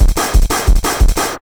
Index of /90_sSampleCDs/USB Soundscan vol.01 - Hard & Loud Techno [AKAI] 1CD/Partition A/01-180TBEAT